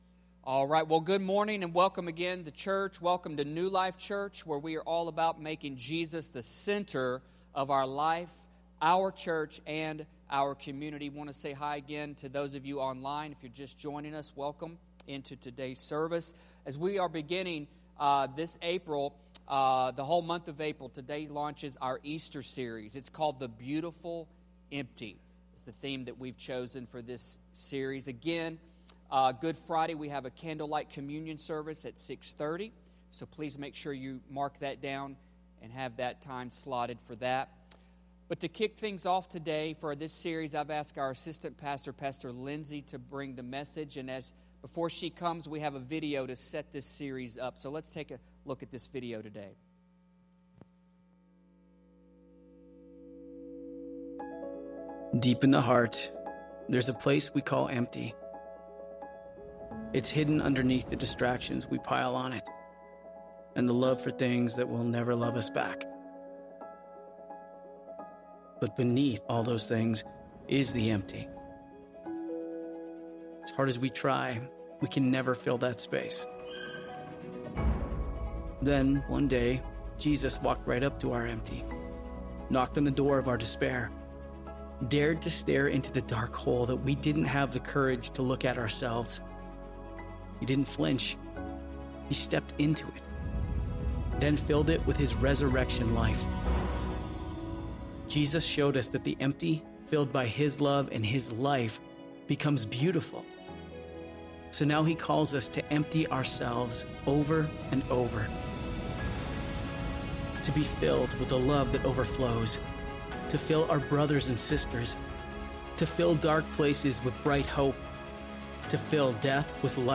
New Life Church Sermons